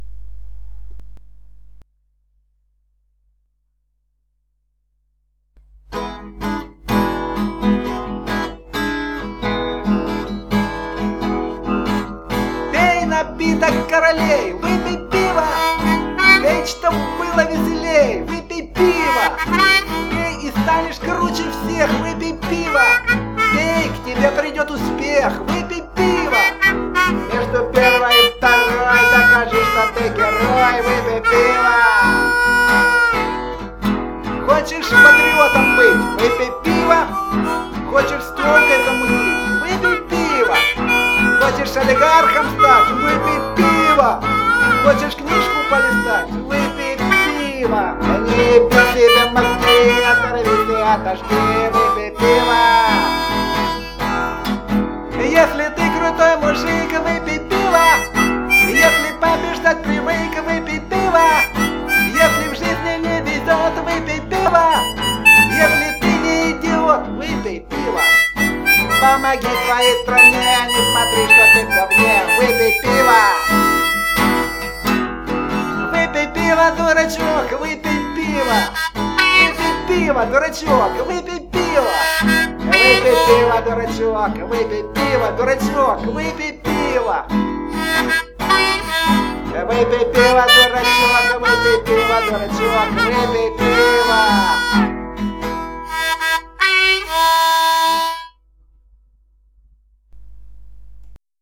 Во вложении - звуковой файл - схема песенки
Рубрика: Поезія, Авторська пісня